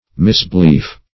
Misbelief \Mis`be*lief"\, n.